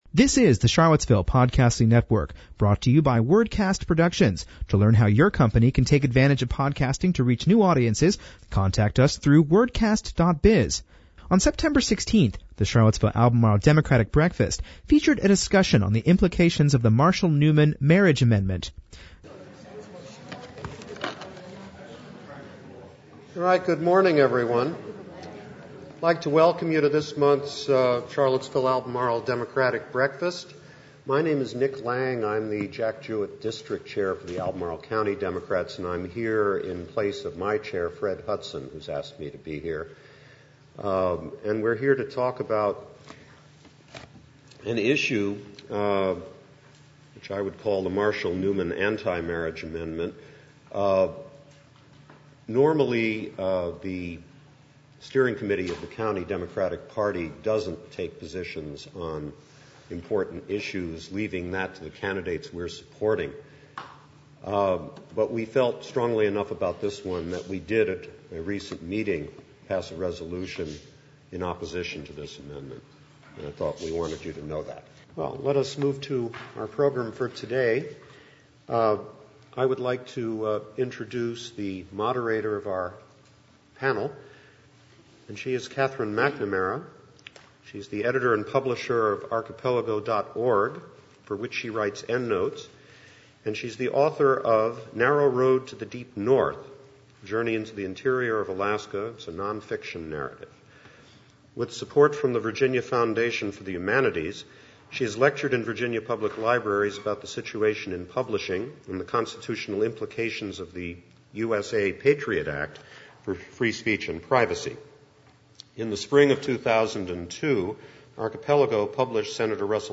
On September 16th, the Charlottesville-Albemarle Democratic Breakfast featured a discussion of the Implications of the Marshall / Newman “Marriage” Amendment.